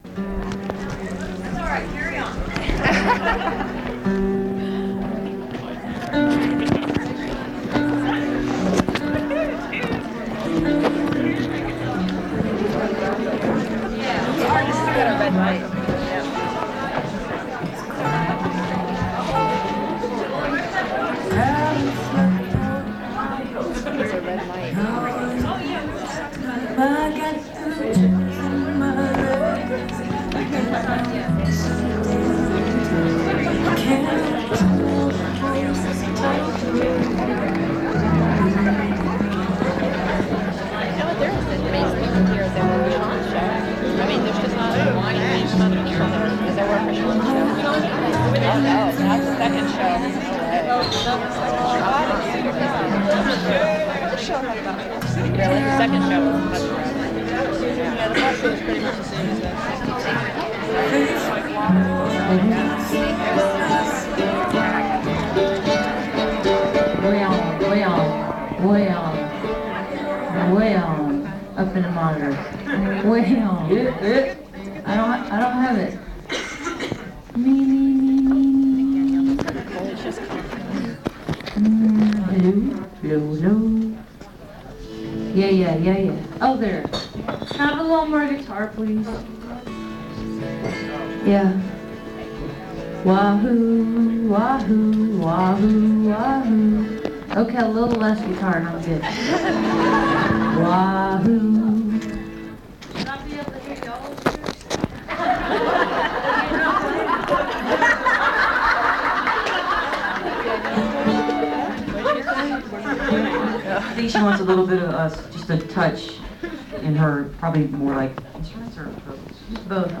(songwriters in the round)
01. soundcheck (2:44)